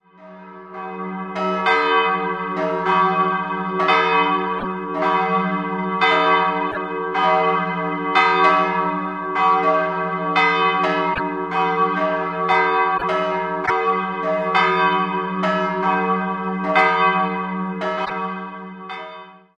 2-stimmiges Kleine-Terz-Geläute: e'-g'
Glocke 1
e'-8
Glocke 2 g'-5
Halberstadt_Moritz.mp3